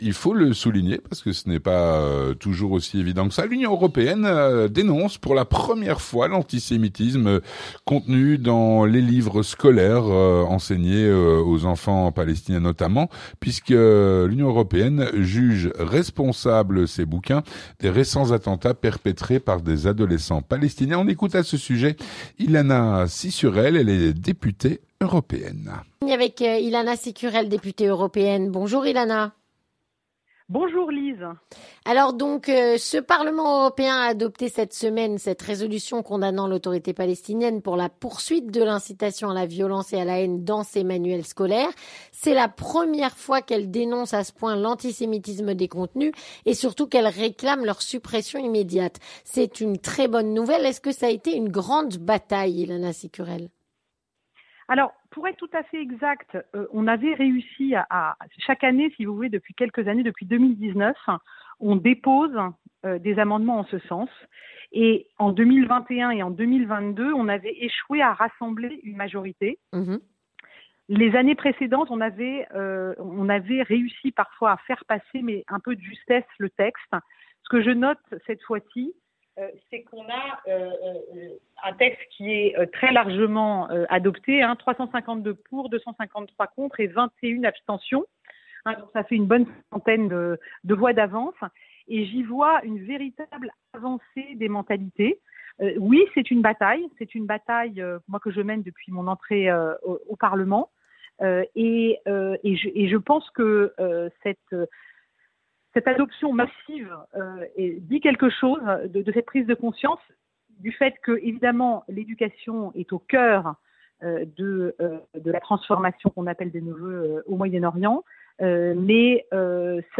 Entretien du 18h - L'UE juge les manuels scolaires palestiniens antisémites
Avec Ilana Cicurel, députée au Parlement Européen